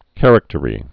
(kărək-tə-rē, kə-răk-)